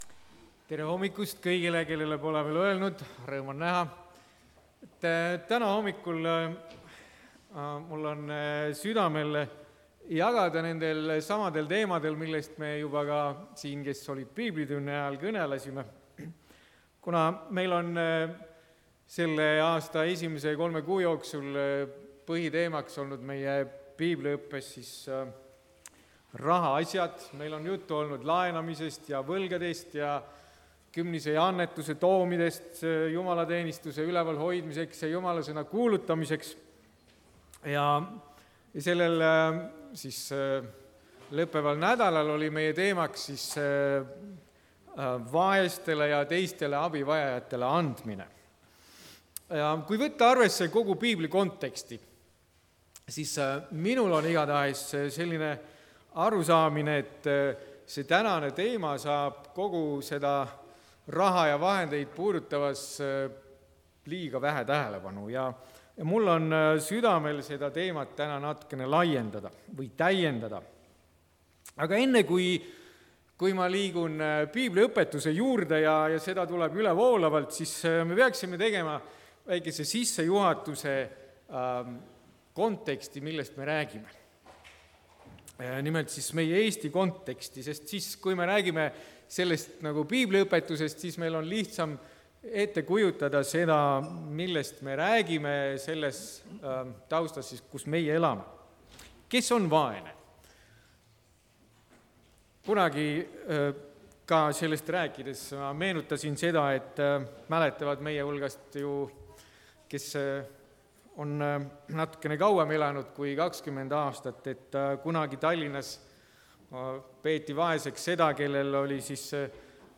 Jutlused
Ainus viis koguda varandust taevasse. (Tallinnas)